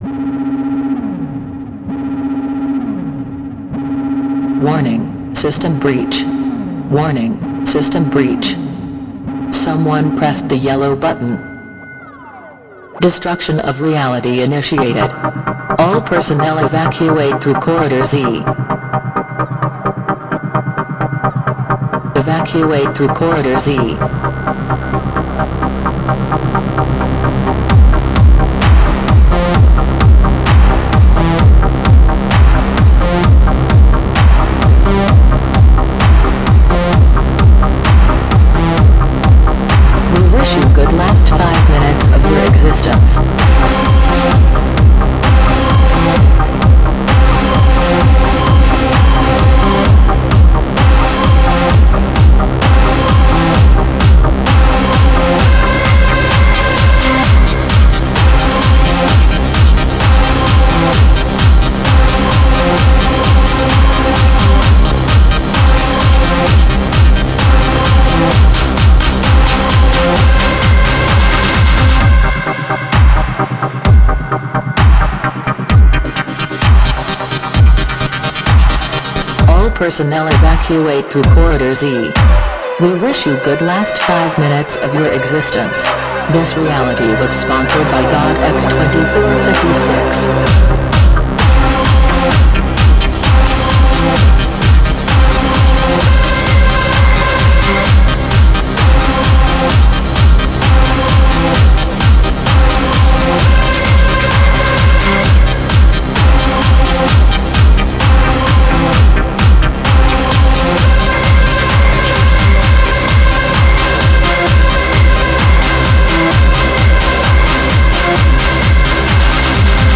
EBM, Industrial, Dark Electro, Cyberindustrial, dark techno